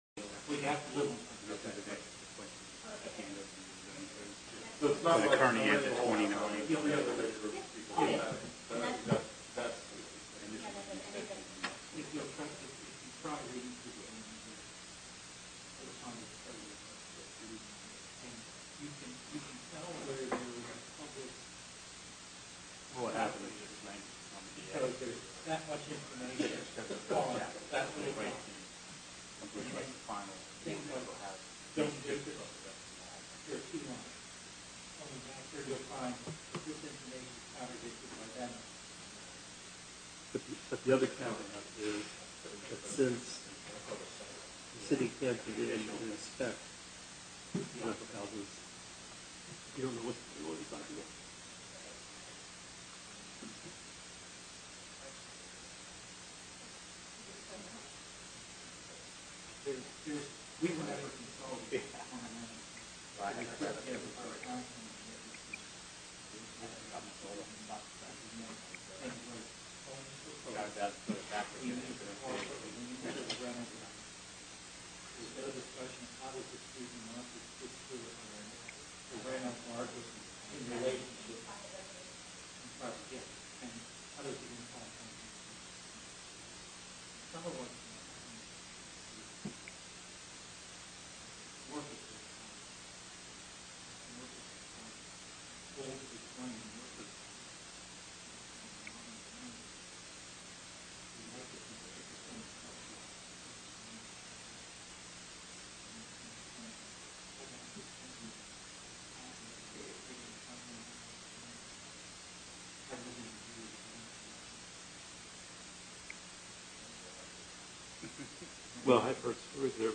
Listen to the audio from the April 4, 2017 Planning Commission meeting here.